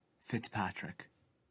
Fitzpatrick (/fɪtsˈpætrɪk/
Fitzpatrickpronunciation.ogg.mp3